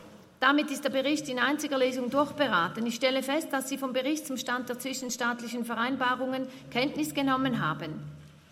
Session des Kantonsrates vom 12. bis 14. Juni 2023, Sommersession